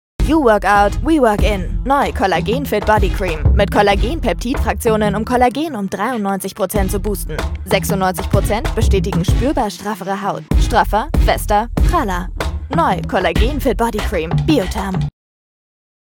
hell, fein, zart
Jung (18-30)
Eigene Sprecherkabine
Off, Commercial (Werbung)